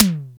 TOM TM035.wav